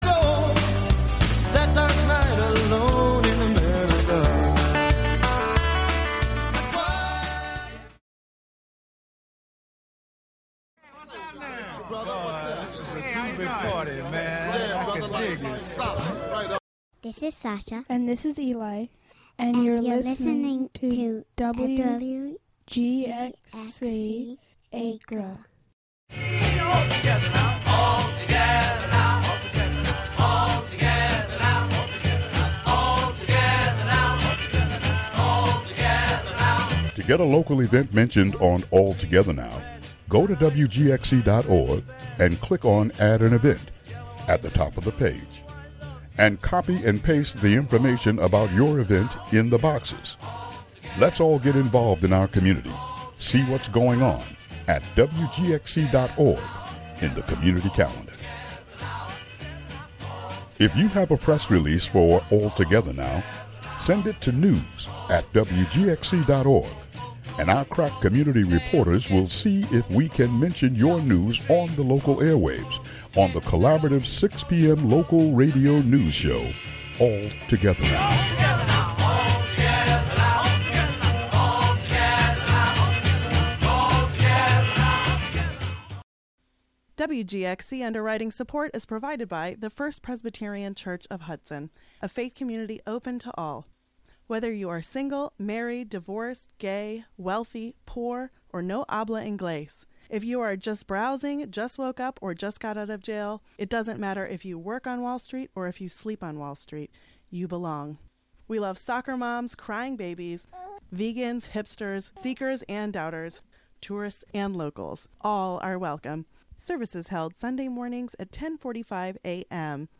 An interview from WOOC-LP about Cornell University...
"All Together Now!" is a daily news show brought to you by WGXC-FM in Greene and Columbia counties.
"All Together Now!" features local and regional news, weather updates, feature segments, and newsmaker interviews.